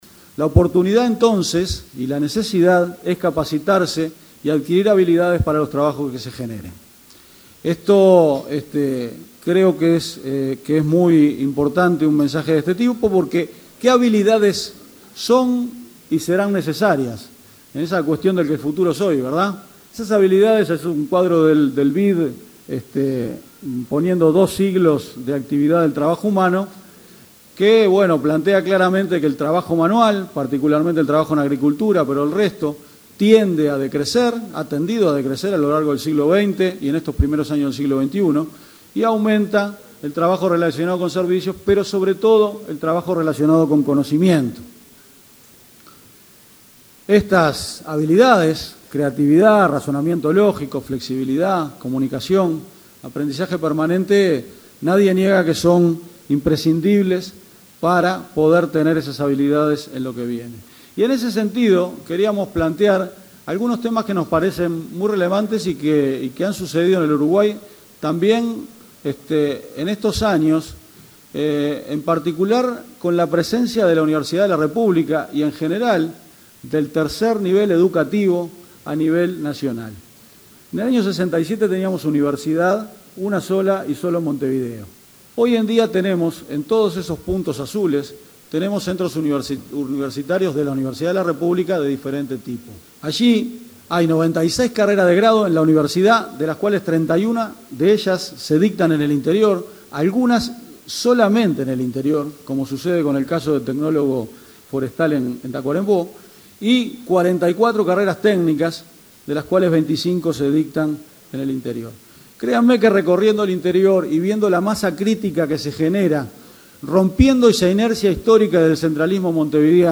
El director de la OPP, Álvaro García, en ADM, destacó la necesidad de potenciar la capacitación para los trabajos del futuro para superar la automatización laboral. Recordó que existen 797.000 dispositivos del Plan Ceibal y todos los centros educativos tienen conectividad.